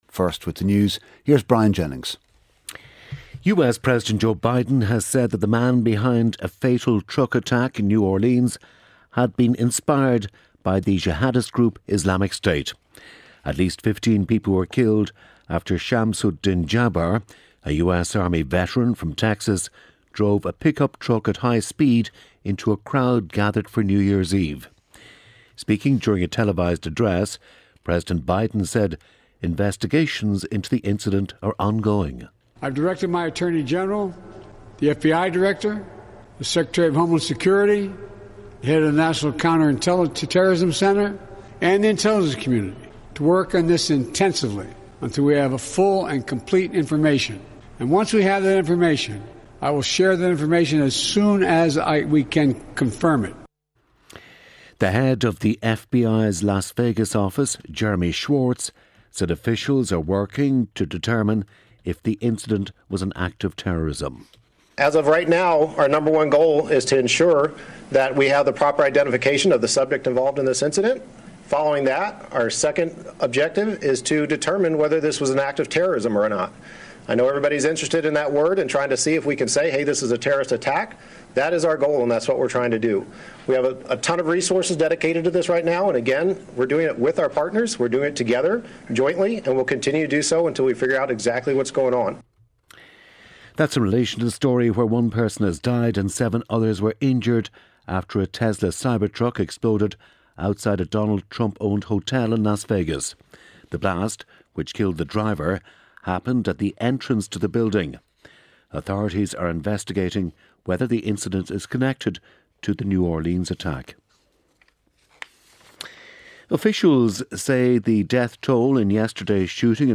RTÉ's flagship news and current affairs radio programme and the most listened-to show in Ireland, featuring the latest news and analysis with Gavin Jennings, Audrey Carville, Áine Lawlor and Mary Wilson.